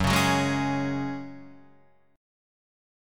F# Suspended 2nd